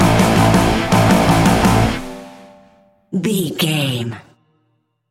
Ionian/Major
hard rock
instrumentals